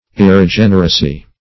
Irregeneracy \Ir`re*gen"er*a*cy\, n.